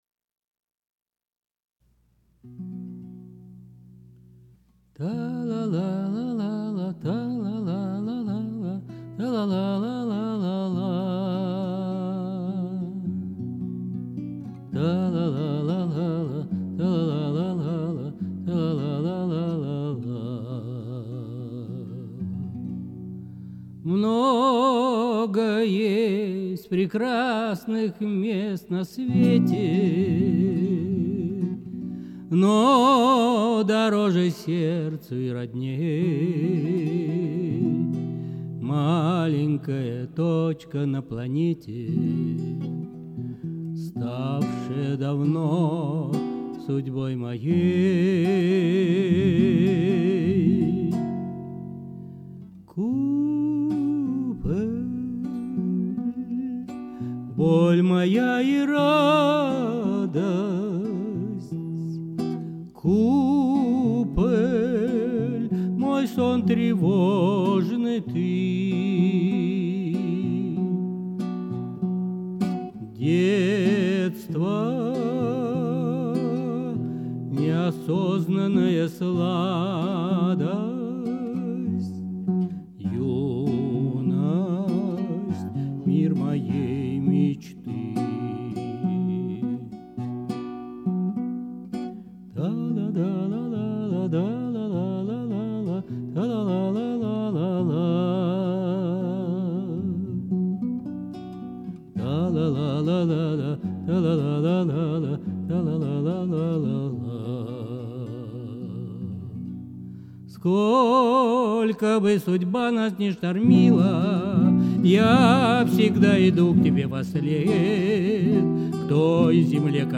Music, vocal, guitar